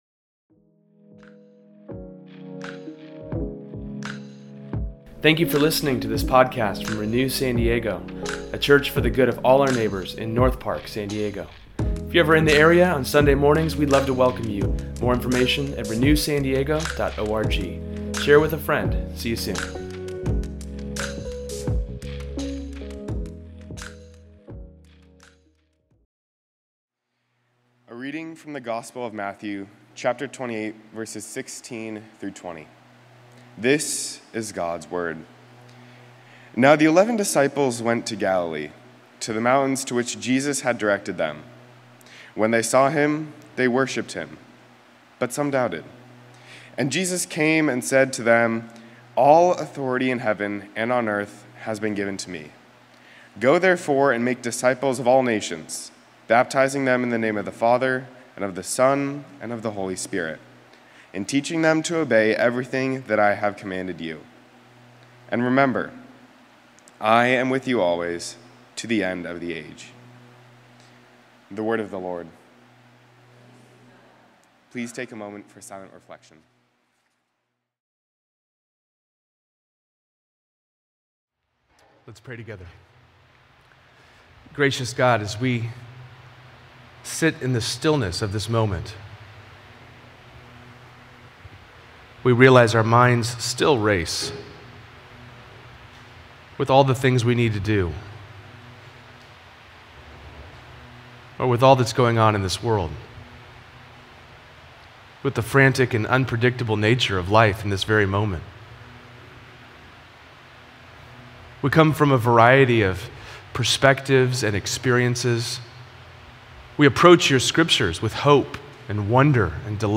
In today’s sermon, we learn about the calling we have from Jesus, and how he is with us always, even when it feels difficult.